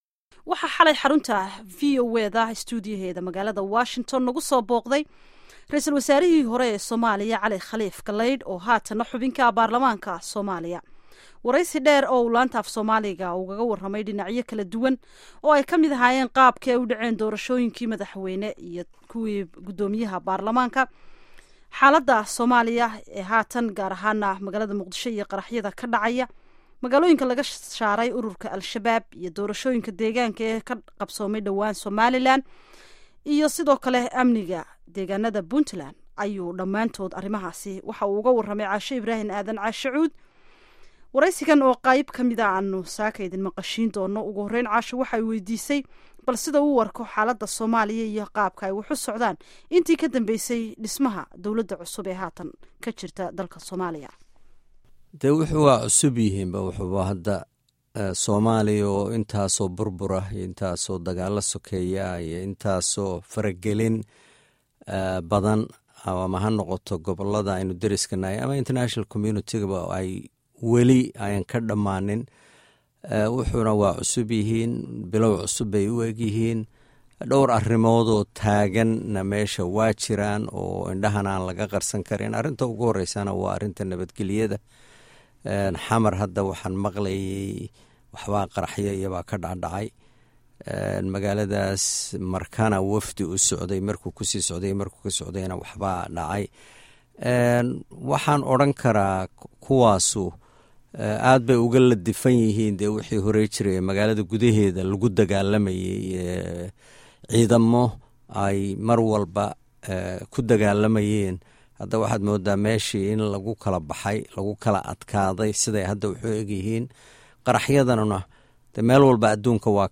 Wareysiga Prof. Cali Kh. Galaydh